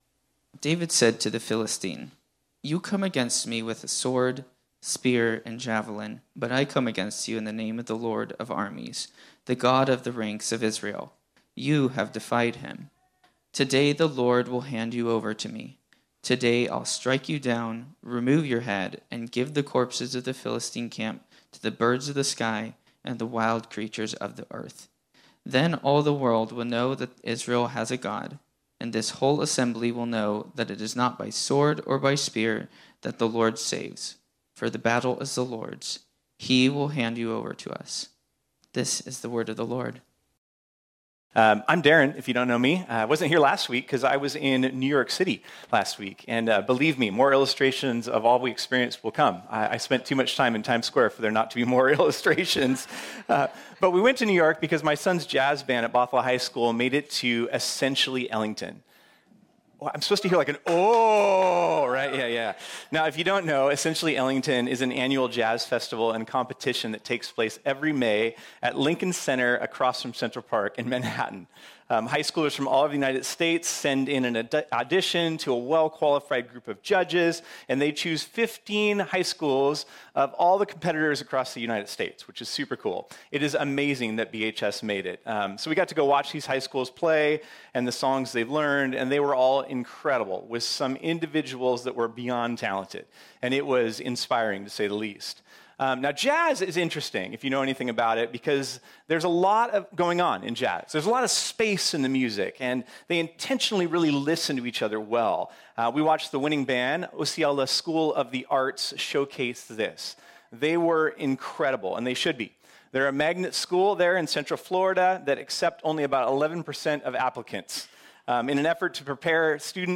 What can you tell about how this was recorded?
This sermon was originally preached on Sunday, December 12, 2021.